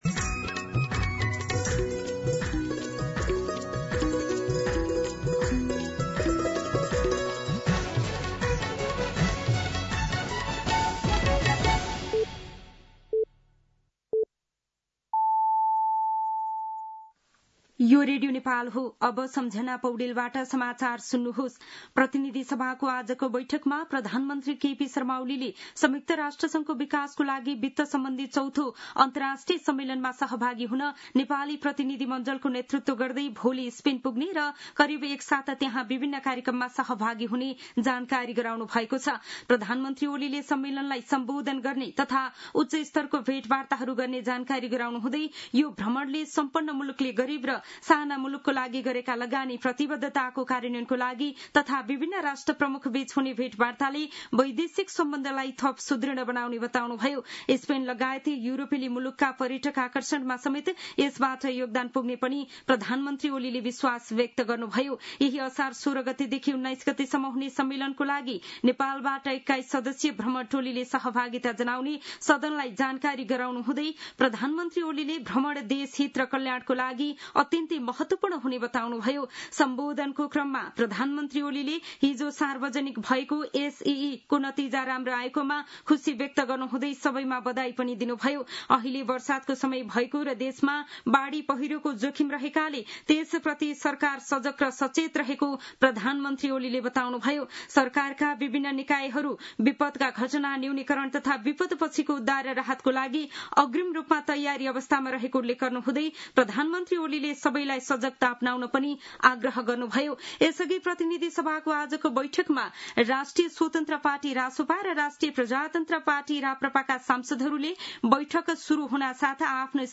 दिउँसो १ बजेको नेपाली समाचार : १४ असार , २०८२
1-pm-Nepali-News-4.mp3